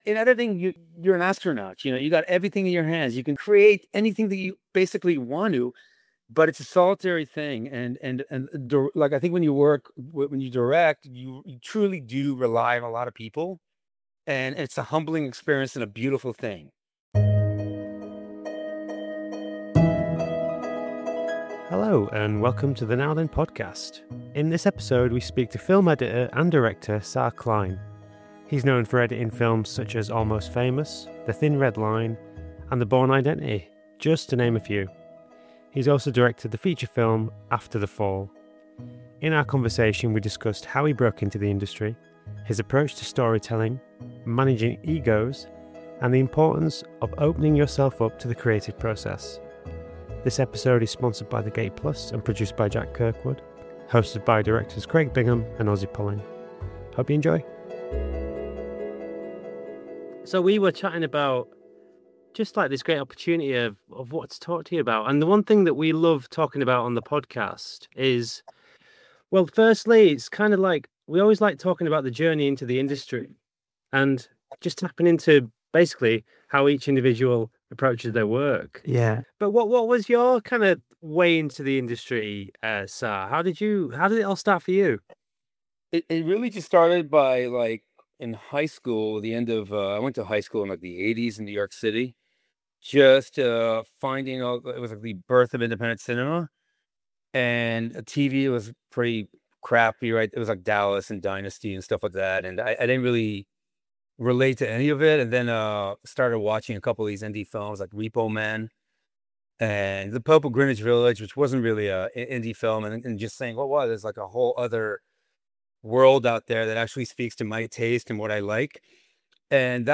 In this conversation we speak to director